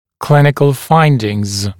[‘klɪnɪkl ‘faɪndɪŋz][‘клиникл ‘файндинз]результаты клинических исследований, клинические наблюдения